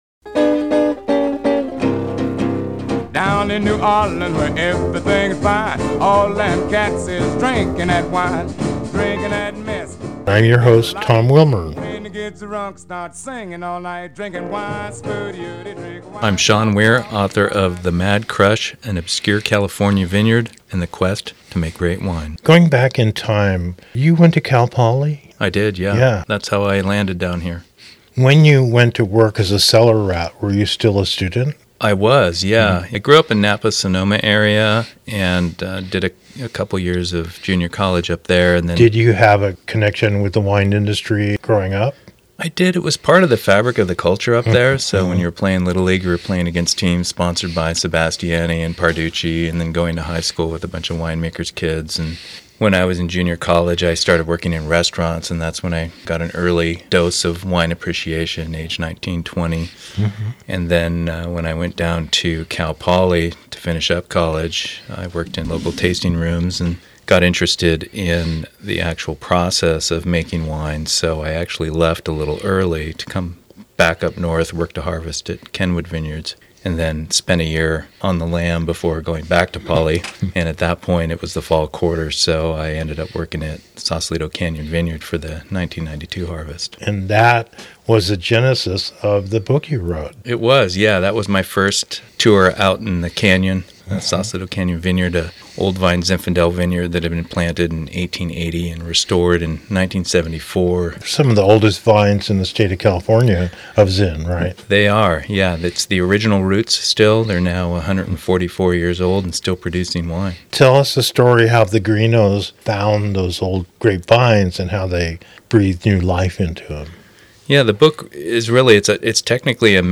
recorded live on location across America and around the world